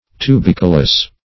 tubicolous - definition of tubicolous - synonyms, pronunciation, spelling from Free Dictionary
Search Result for " tubicolous" : The Collaborative International Dictionary of English v.0.48: Tubicolous \Tu*bic"o*lous\, a. [See Tubicolae .]